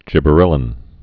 (jĭbə-rĕlĭn)